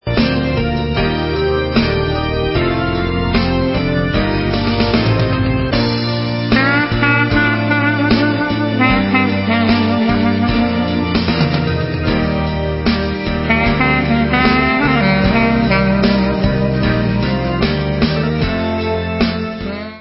sledovat novinky v oddělení Pop/Instrumental